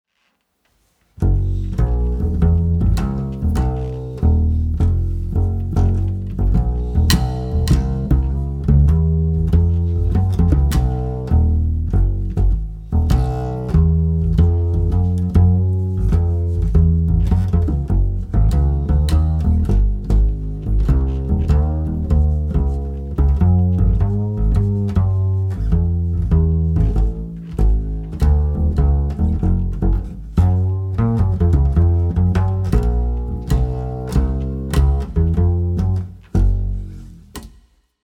lät bra det där, definitivt en bra mick för det mesta bevisligen.